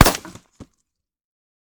main Divergent / mods / JSRS Sound Mod / gamedata / sounds / material / bullet / collide / wood03gr.ogg 38 KiB (Stored with Git LFS) Raw Permalink History
wood03gr.ogg